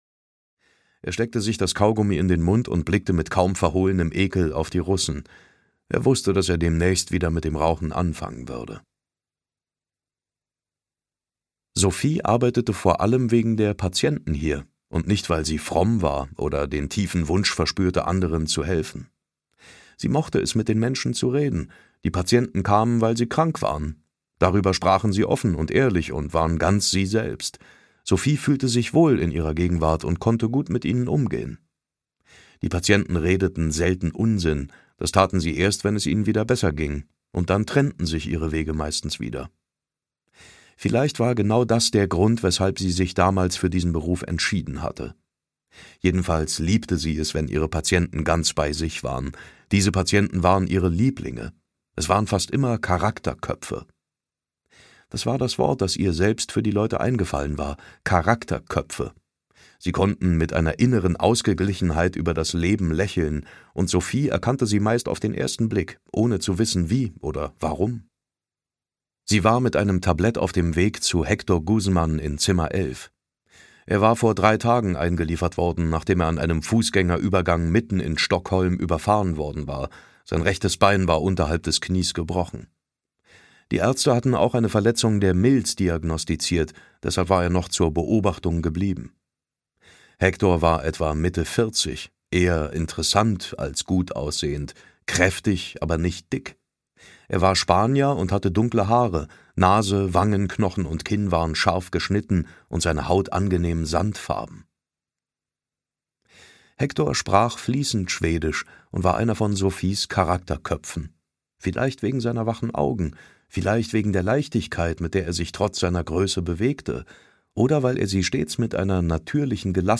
Hörbuch Unbescholten (Die Sophie-Brinkmann-Trilogie 1), Alexander Söderberg.